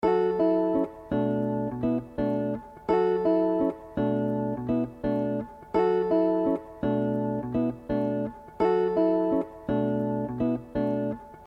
BPM 168 BORTHERS GONNA WORK IT OUT.mp3